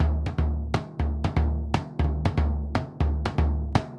Bombo_Baion_120_1.wav